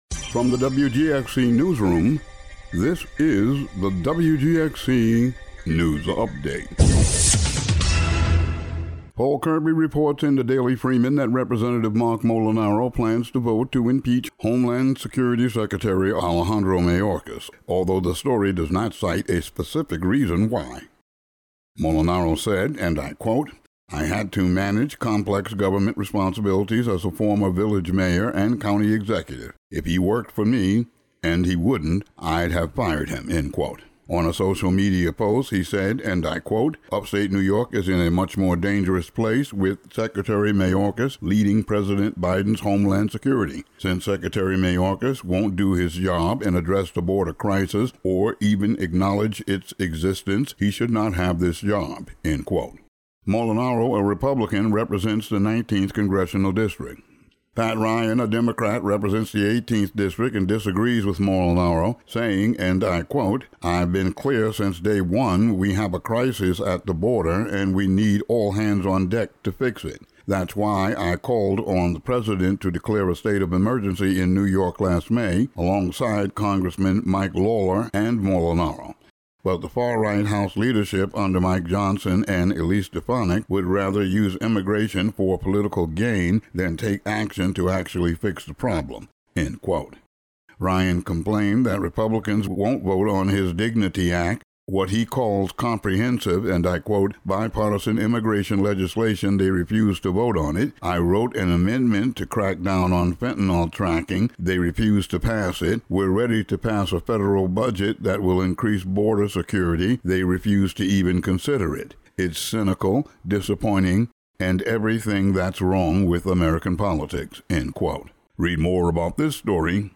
Today's daily local audio news update.